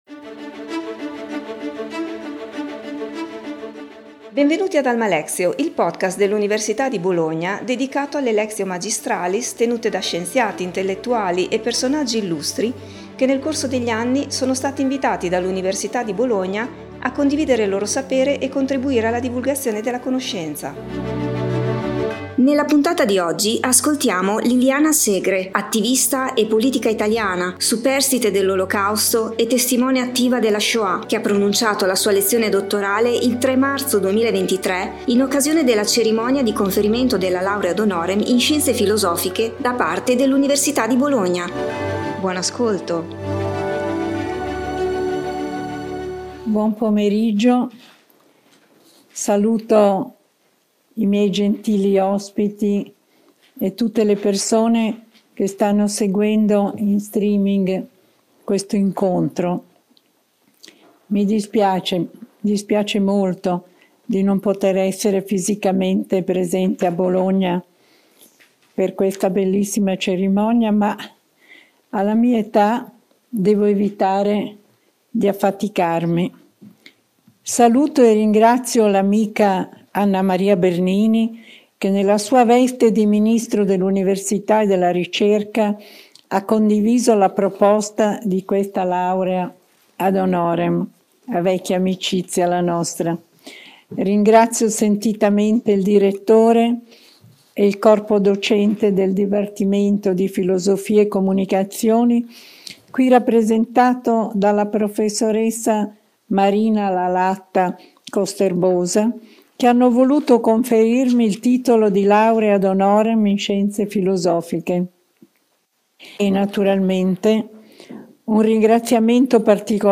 Liliana Segre, attivista e politica italiana, superstite dell'Olocausto e testimone attiva della Shoah, ha pronunciato la sua lezione dottorale il 3 marzo 2023 in occasione della Cerimonia di Conferimento della laurea ad honorem in Scienze Filosiche da parte dell'Università di Bologna.